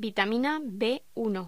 Locución: Vitamina B1